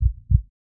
Heartbeat SFX
heartbeat.wav